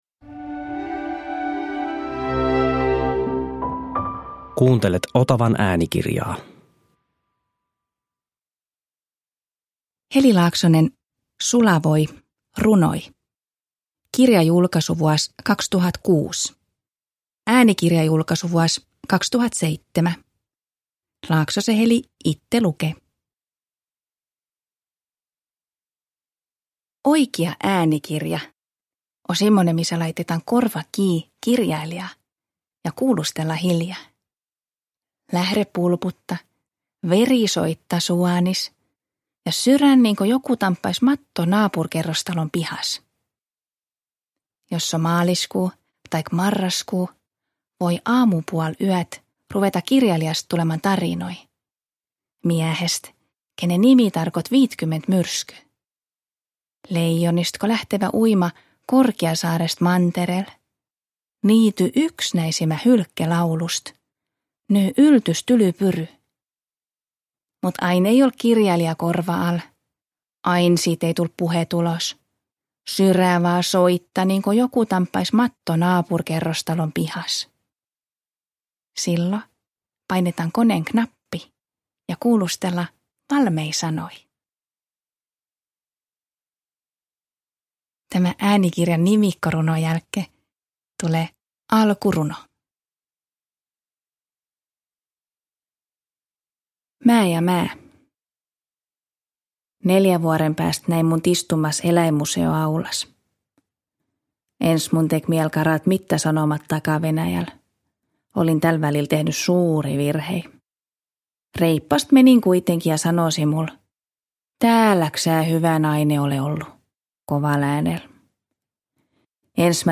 Lounaismurteella kirjoittavan Laaksosen raikkaat säkeet uppoavat suomalaisiin kuin voi kuumaan puuroon.
Ratio Sulavoin suorassa lähetyksessä Heli Laaksonen lukee runojaan ja kertoo runoilijan ja kiertävän sanamaijan elämästä.
Runojen lomassa soittaa tunnelmaan sopivaa musiikkia kansanmusiikkiyhtye Rymäkkä.
Uppläsare: Heli Laaksonen